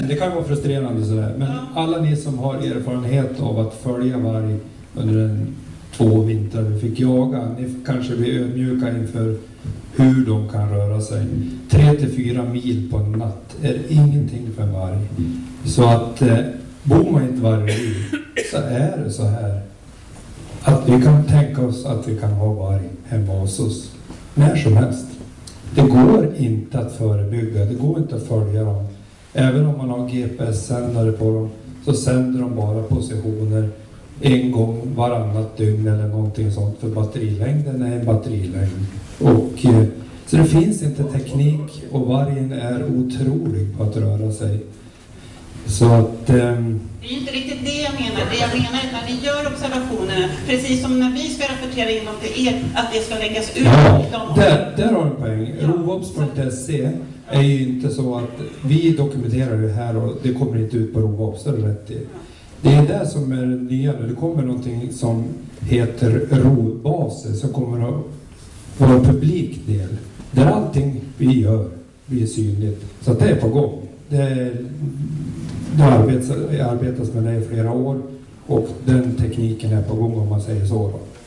Länsstyrelsen Gävleborg kallade till vargmöte.
Färilarevyn var platsen för mötet på onsdagskvällen och redan en halvtimme innan start var det fullt.